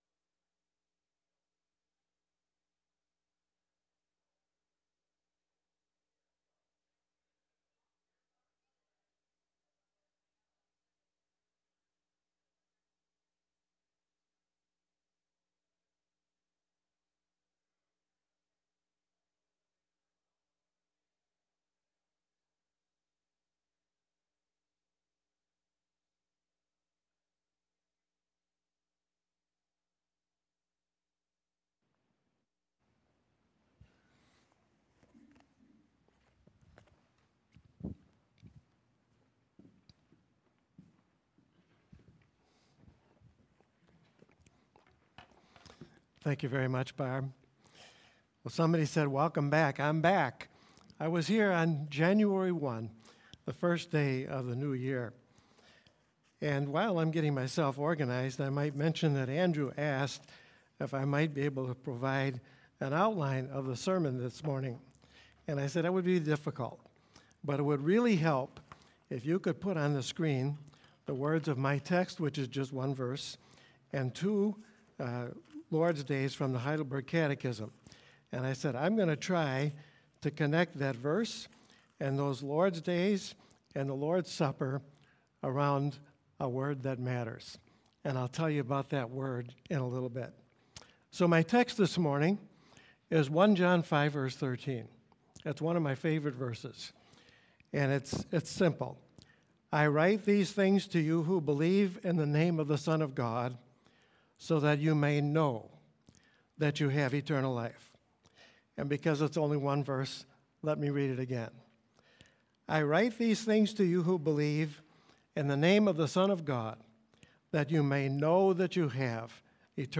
Passage: 1 John 5:13 Worship Service Video February 26 Audio of Message Download Files Notes Bulletin « Connect